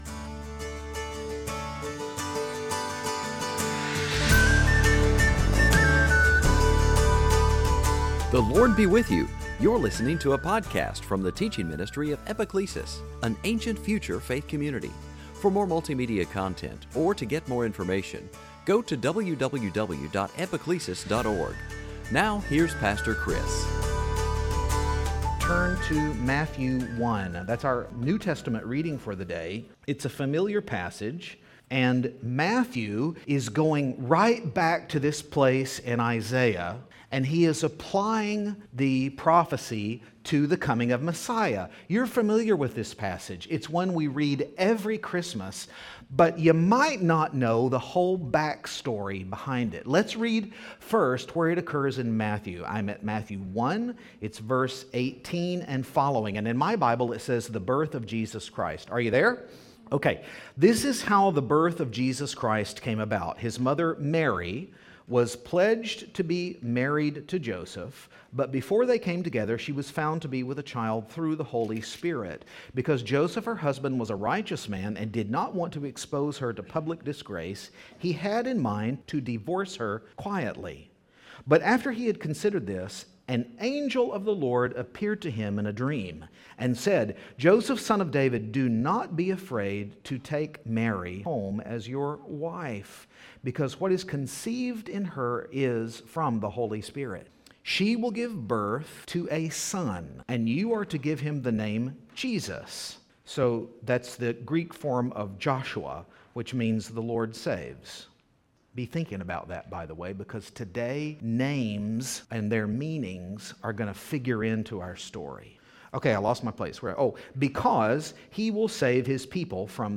Series: Sunday Teaching
Service Type: Advent